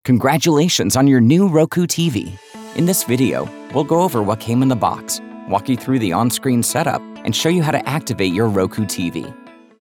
Voice Over Read Styles
#9 Youthful, Energetic, Lively